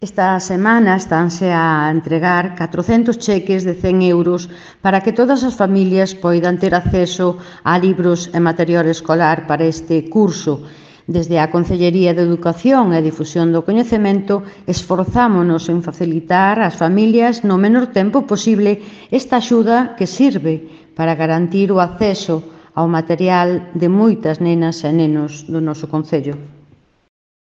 Audio La concelleira de Educación, Maite Ferreiro, sobre las ayudas de los libros de texto | Descargar mp3